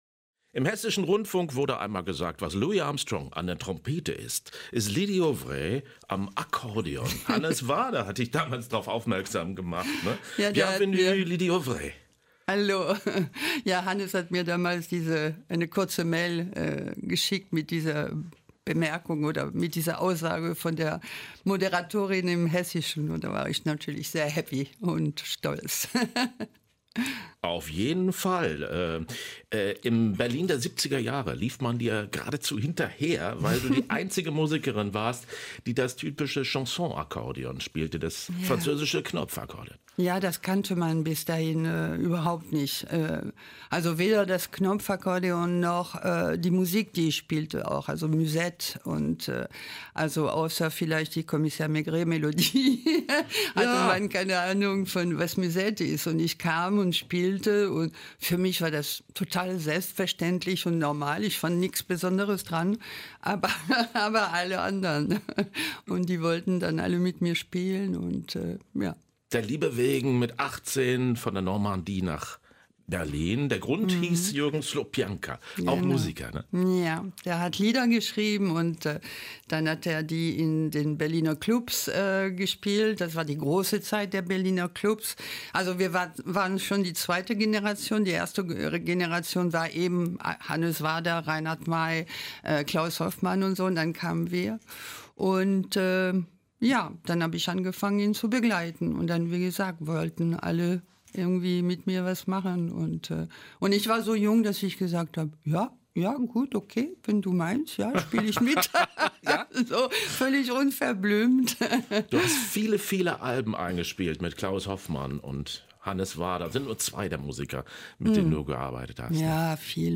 Ein Interview mit Lydie Auvray (Akkordeonistin und Sängerin)